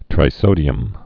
tri·so·di·um
(trī-sōdē-əm)